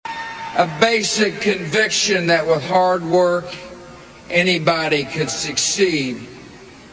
Slow Down Audio